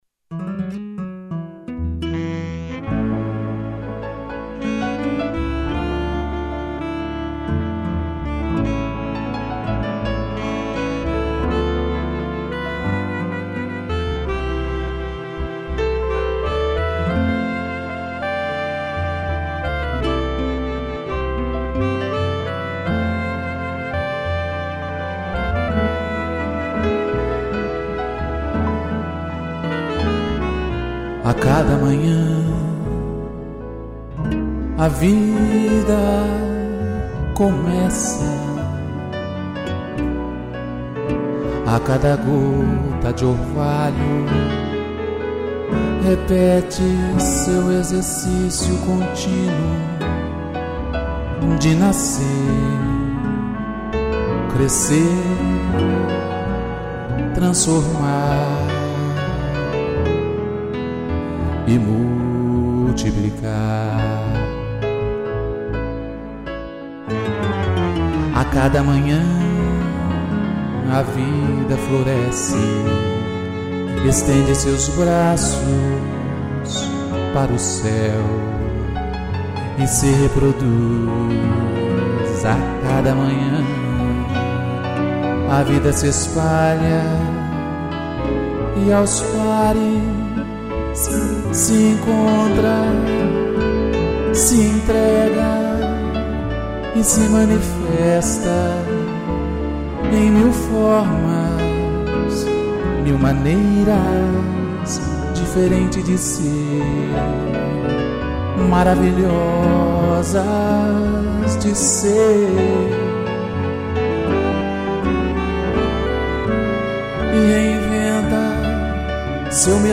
voz
piano